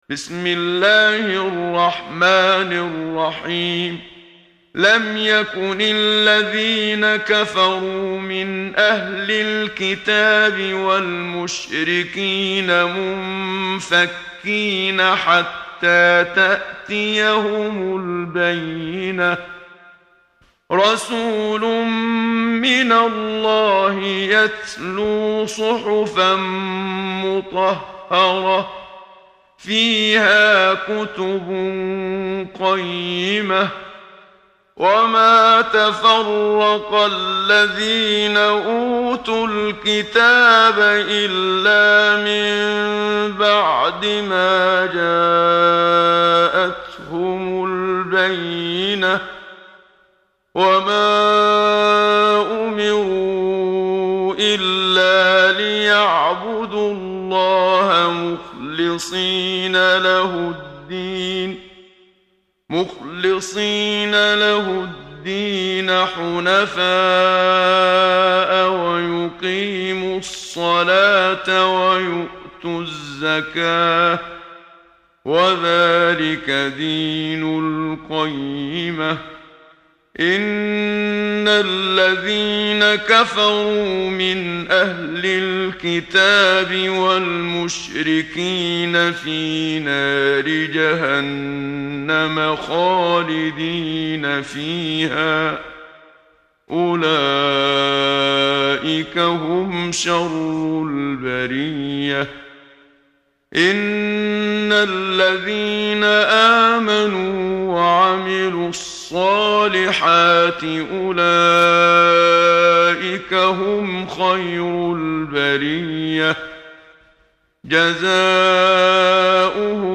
محمد صديق المنشاوي – ترتيل